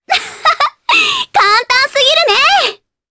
Reina-Vox_Victory_jp.wav